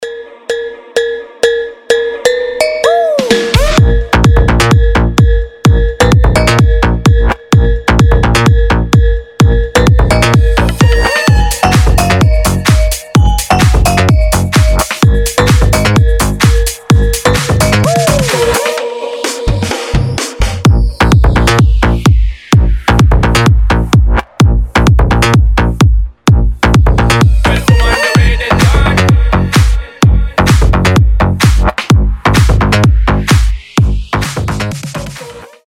• Качество: 320, Stereo
громкие
жесткие
мощные басы
Bass House
качающие
взрывные
G-House
ремиксы
Крутой, стильный рингтон, музыка басовая, вкусная